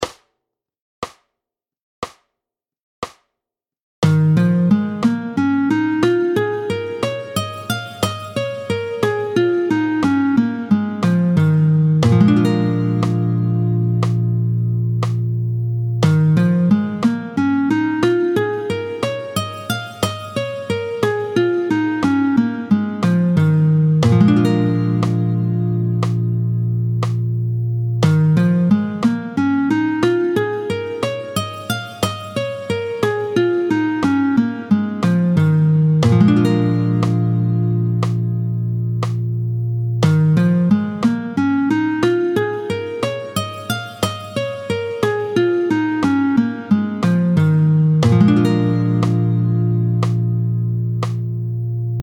31-03 Doigté 3 en Sib, tempo 60